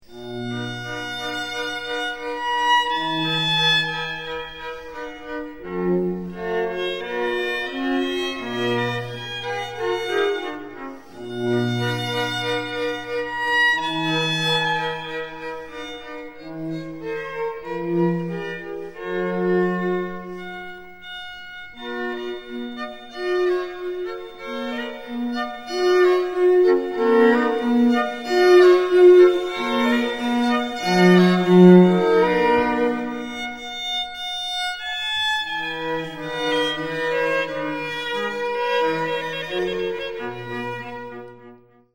first world recording on period instruments
Quartetto per archi